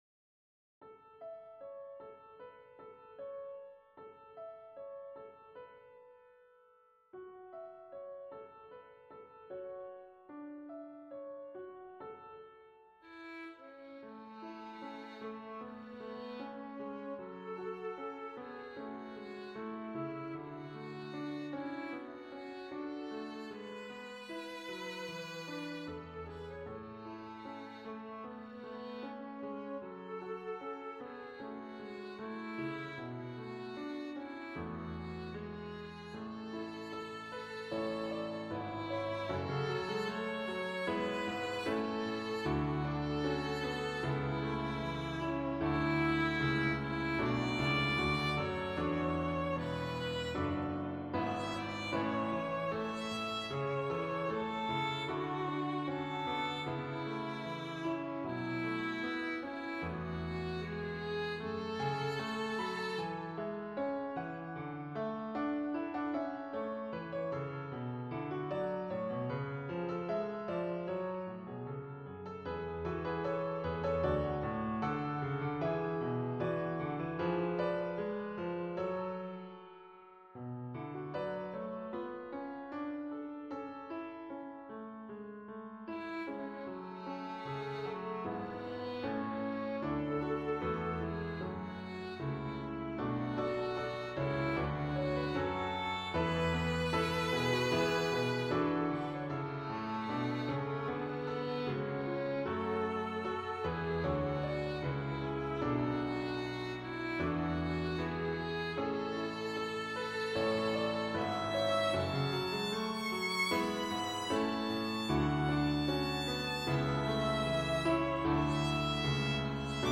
Voicing: Viola and Piano